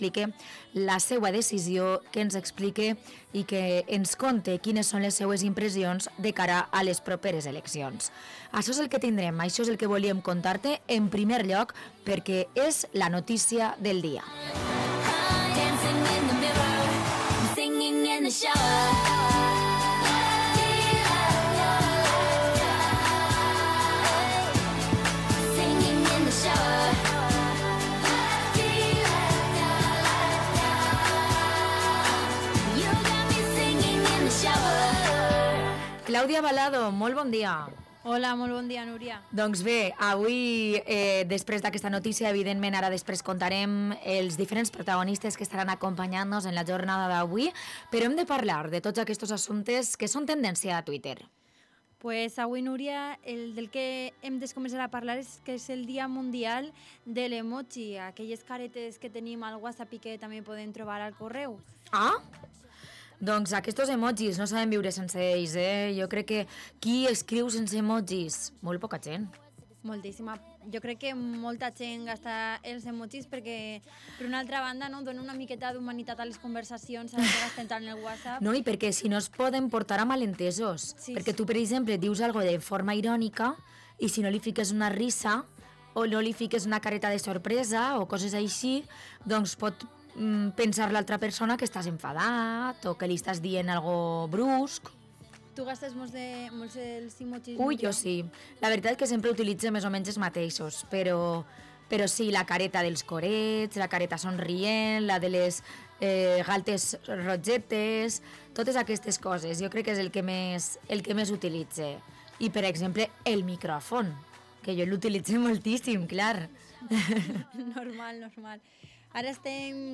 Hemos entrevistamos a Xavi Ochando, portavoz de Compromís Vila-real, tras su anuncio de no presentarse a las próximas elecciones.